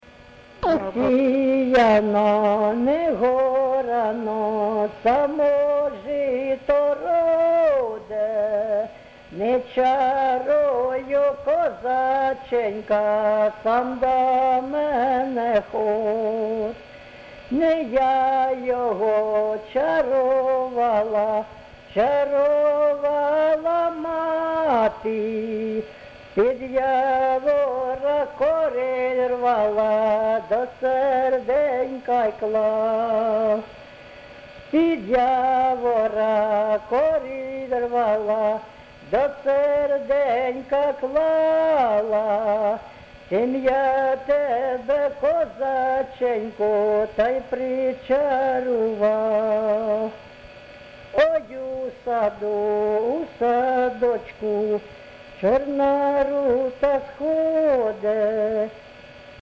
ЖанрПісні з особистого та родинного життя
Місце записум. Антрацит, Ровеньківський район, Луганська обл., Україна, Слобожанщина